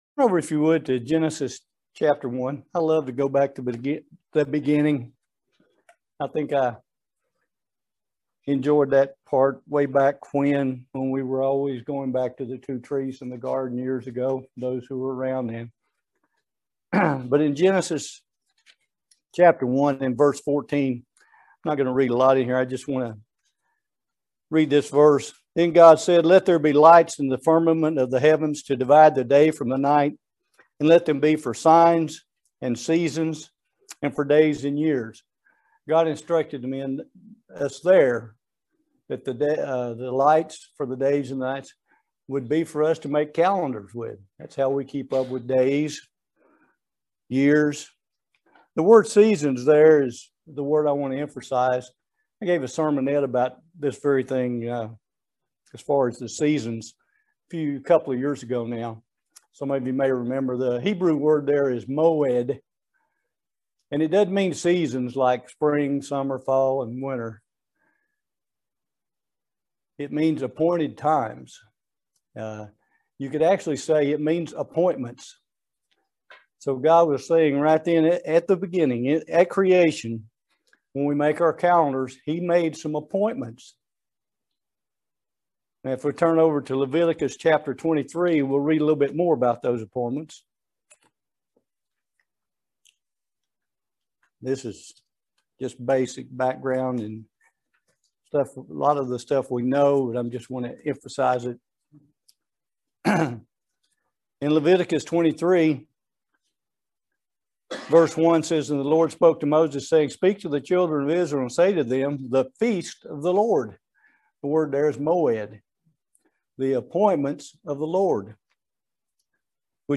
Sermon
Given in Lexington, KY